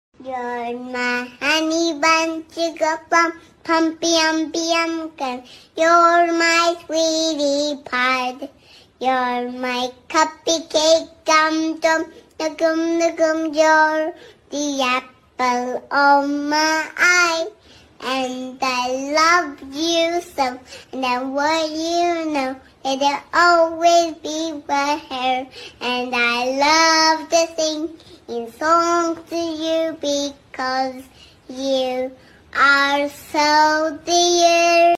🥹💖 This adorable singing AI cat is here to steal your heart!